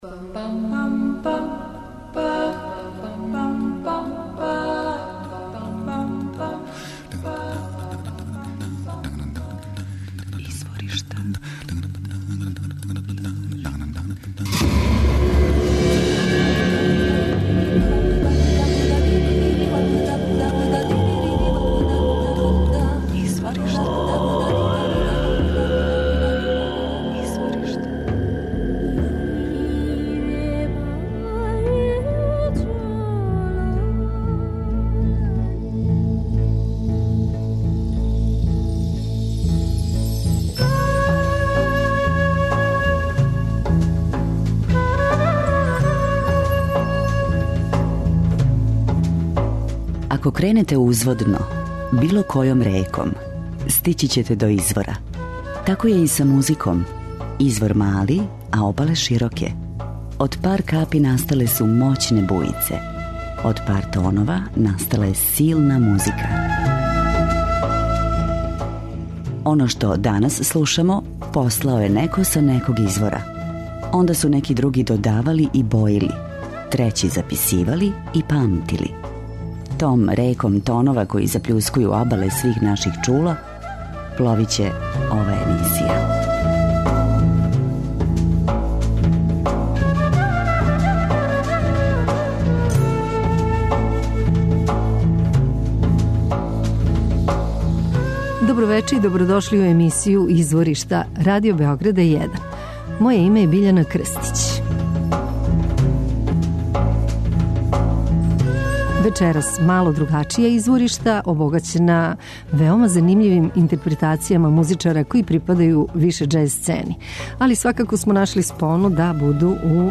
певач, композитор, перкусиониста
хармоника